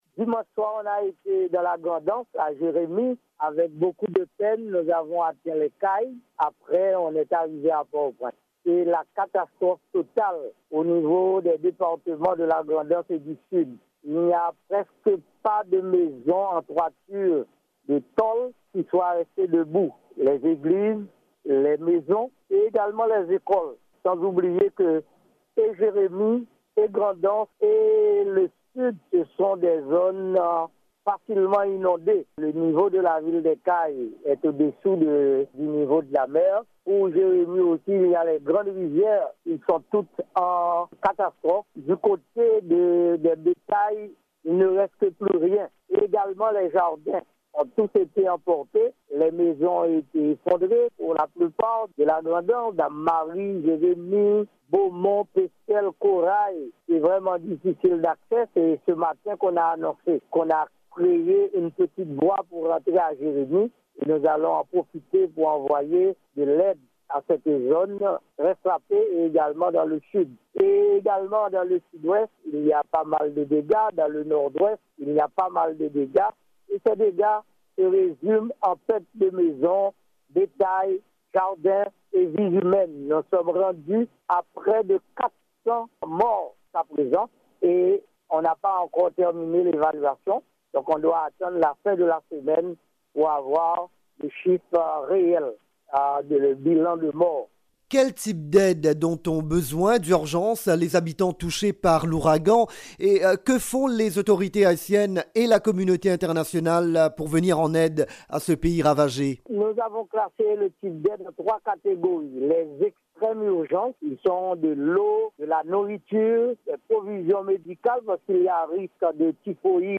Scènes de désolation en Haïti après le passage de l'ouragan Matthew en début de semaine. Sur le terrain, les ONG et secours sont engagés dans une véritable course contre la montre pour venir en aide aux sinistrés. Interview